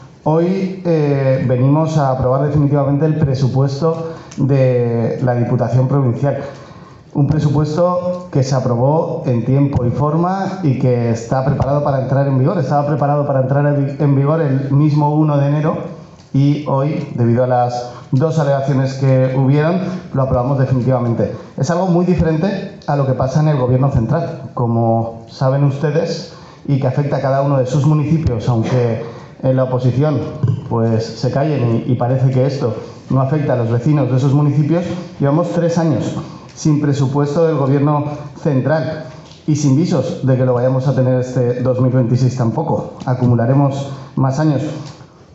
Diputado-Sergio-Toledo-Pleno-aprobacion-definitiva-presupuesto-2026.mp3